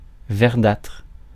Ääntäminen
Ääntäminen France: IPA: /vɛʁ.datʁ/ Haettu sana löytyi näillä lähdekielillä: ranska Käännös Adjektiivit 1. greeny 2. greenish Suku: f .